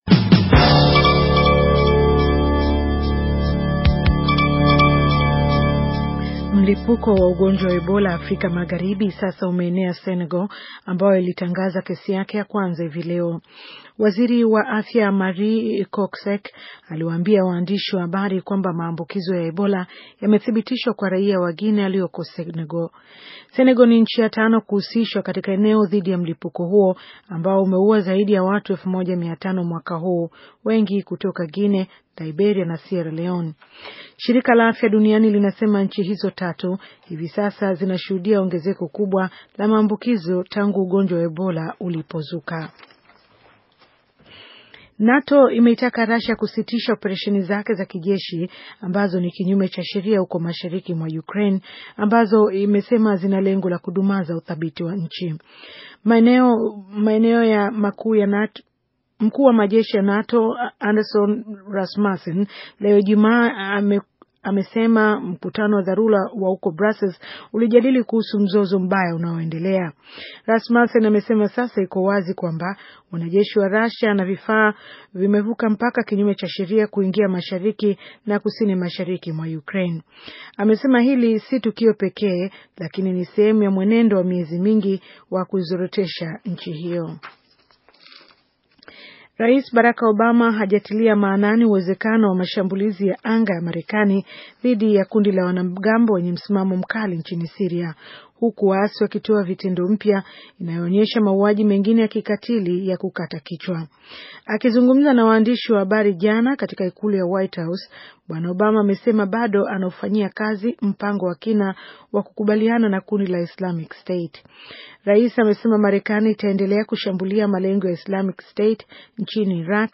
Taarifa ya habari - 6:18